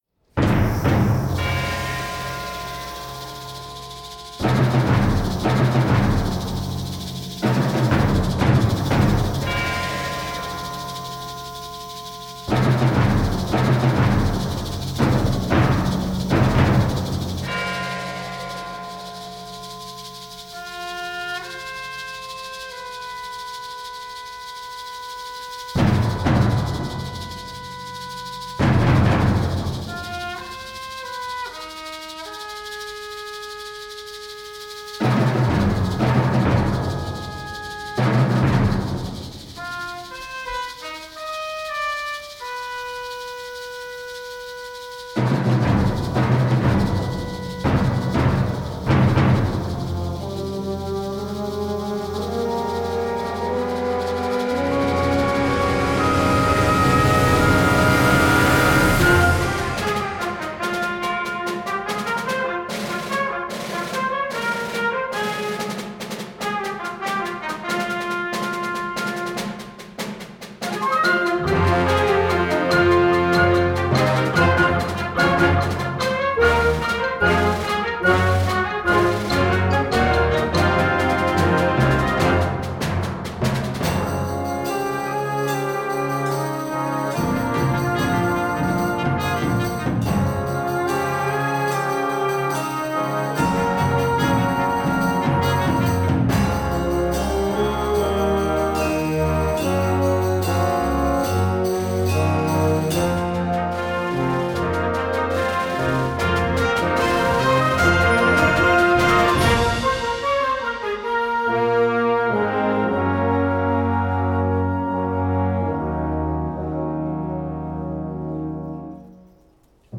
Gattung: Konzertwerk für Blasorchester
Besetzung: Blasorchester